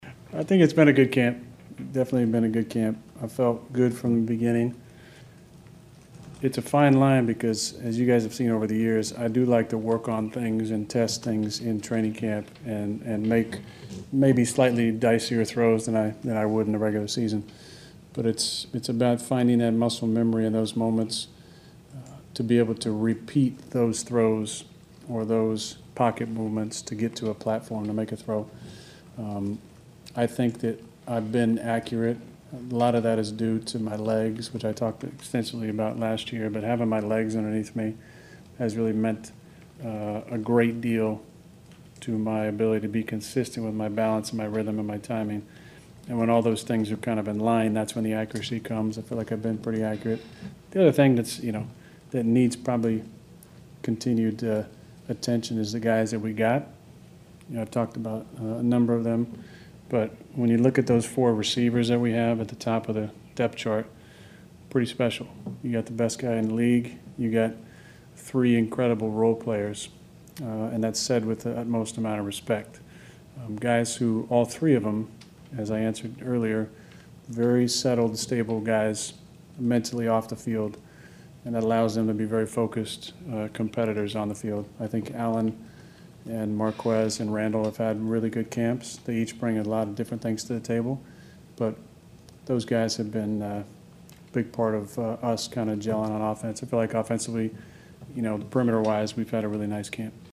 The reigning MVP was the Packer headliner all summer of course with his dispute with the front office and his sudden on time arrival.    Meeting the media Thursday afternoon, he was asked about what by all accounts was a pretty sharp month of camp.